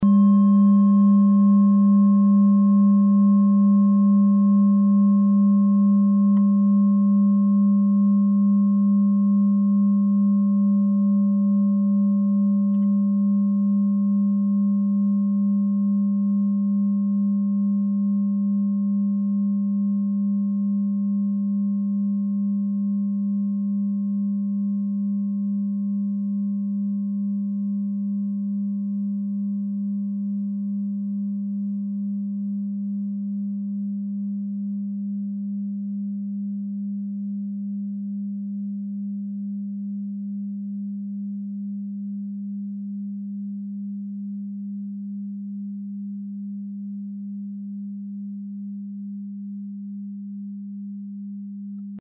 Klangschale TIBET Nr.4
Klangschale-Durchmesser: 20,0cm
Sie ist neu und ist gezielt nach altem 7-Metalle-Rezept in Handarbeit gezogen und gehämmert worden.
Die Pi-Frequenz kann man bei 201,06 Hz hören. Sie liegt innerhalb unserer Tonleiter nahe beim "Gis".
klangschale-tibet-4.mp3